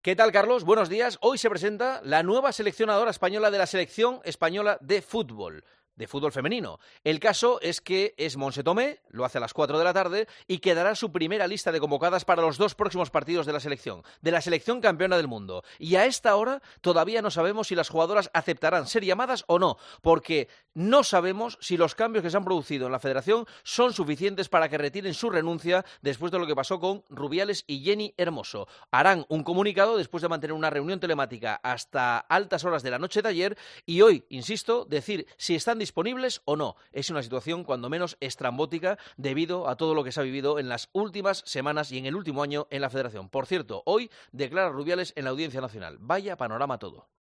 AUDIO: El presentador de 'El Partidazo de COPE' analiza la actualidad deportiva en 'Herrera en COPE'